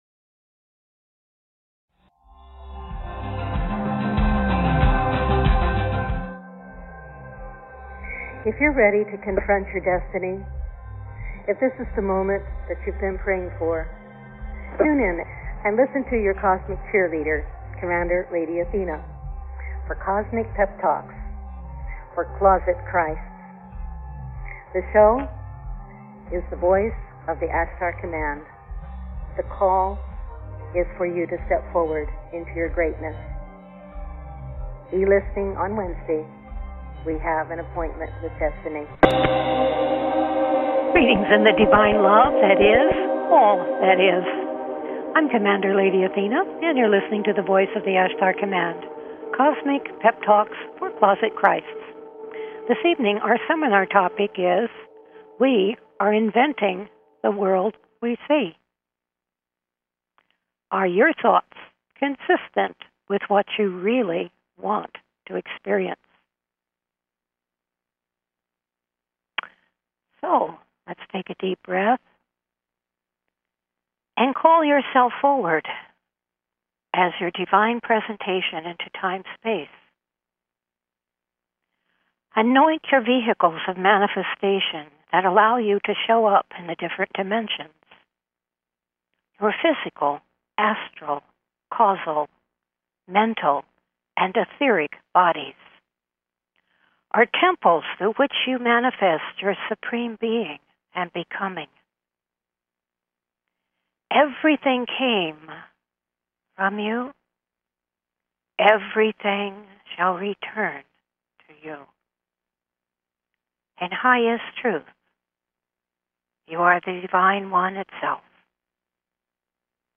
Talk Show Episode, Audio Podcast, WE ARE INVENTING THE WOULD THAT WE SEE WITH OUR EVERY THOUGHT and ARE YOUR THOUGHTS CONSISTENT WITH WHAT YOU REALLY WANT TO EXPERIENCE?